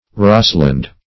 Search Result for " rosland" : The Collaborative International Dictionary of English v.0.48: Rosland \Ros"land\, n. [W. rhos a meadow, a moor + E. land.] heathy land; land full of heather; moorish or watery land.